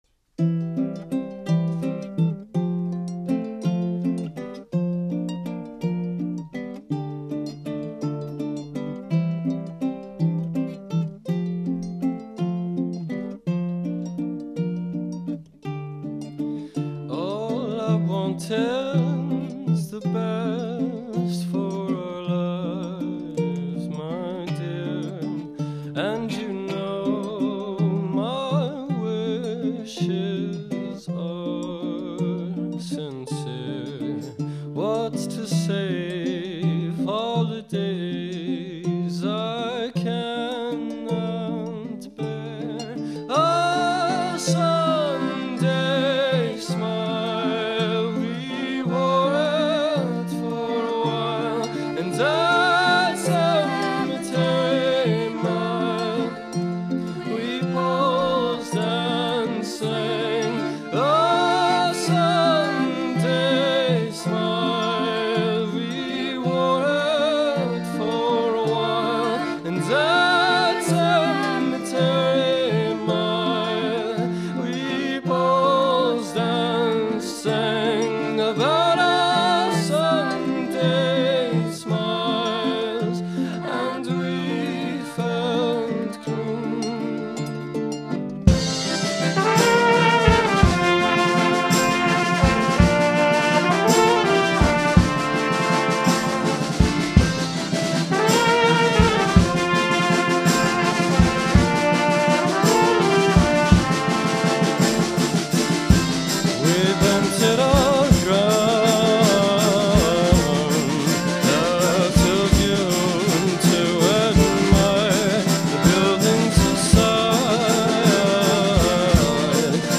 1. Engine Studios (03 Oct 2007)
Album - Live